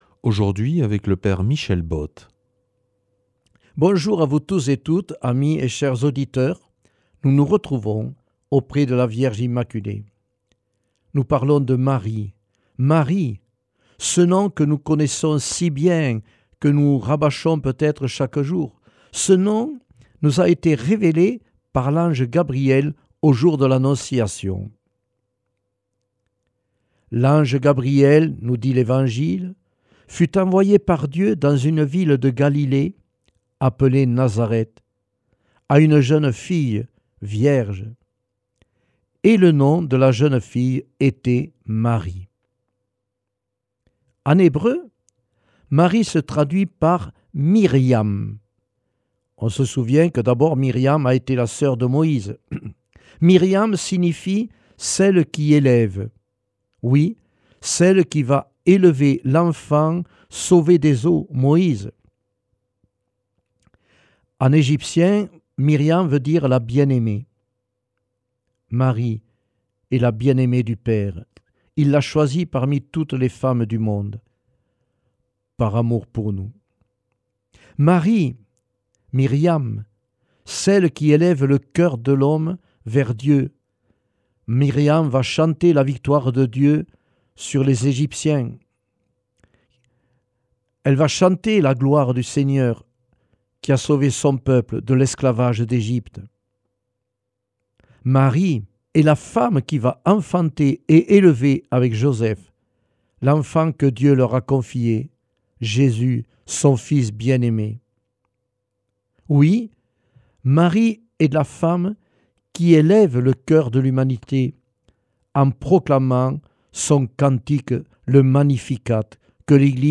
Enseignement Marial du 25 févr.